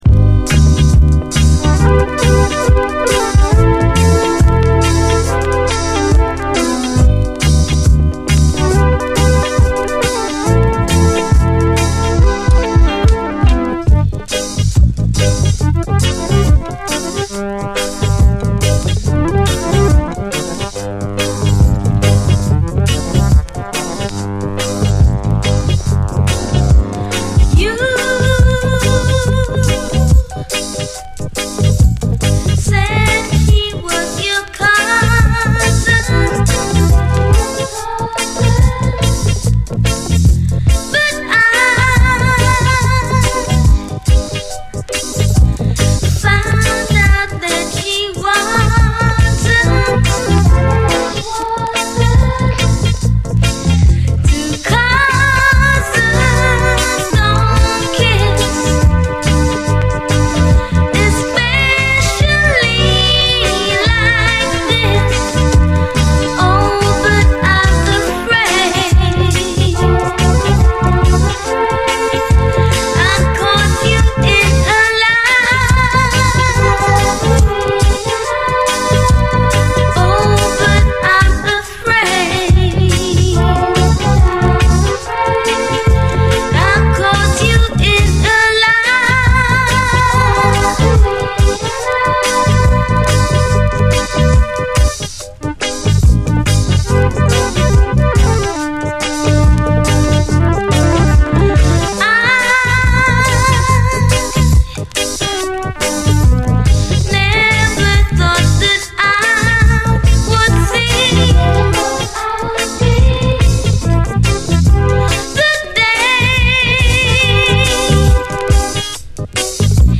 REGGAE
後半はインスト。